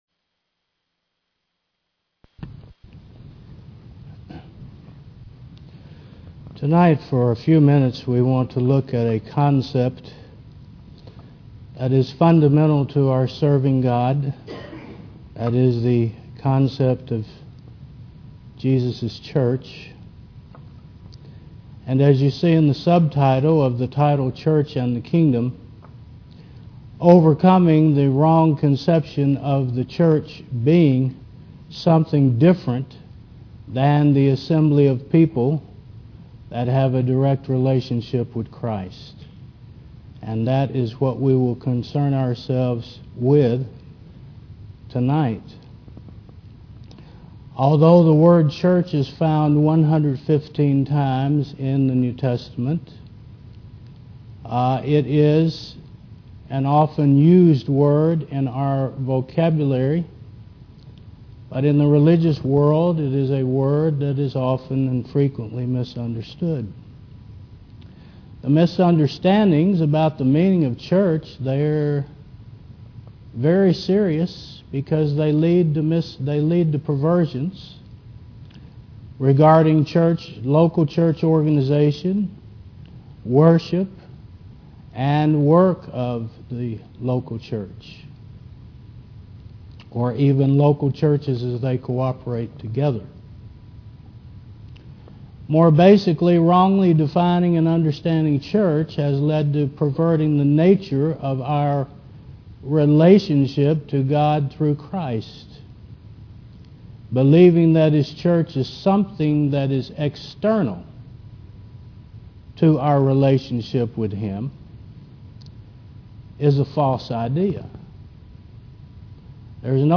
Faith Service Type: Sun. 5 PM The word church is used 115 times in the New Testament and is one of the most misunderstood words in Scripture.